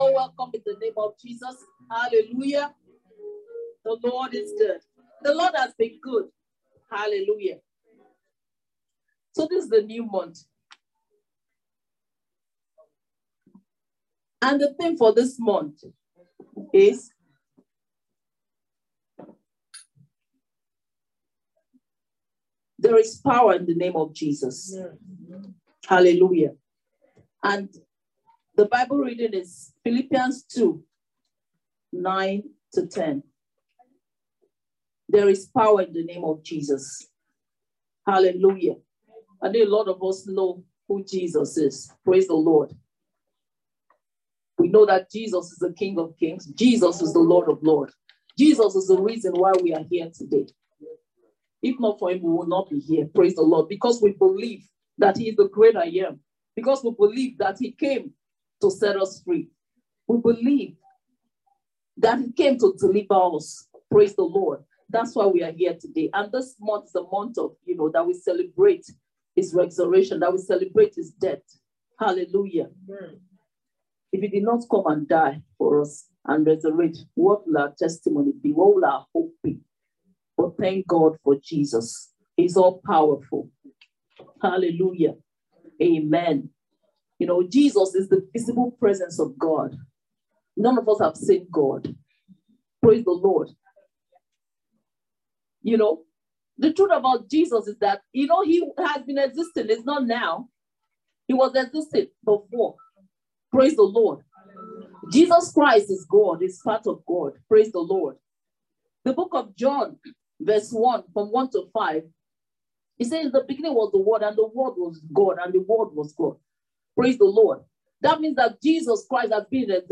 Passage: Philippians 2:9-10 Service Type: Thanksgiving Service « The Name of the Lord Is A Strong Tower Fatherhood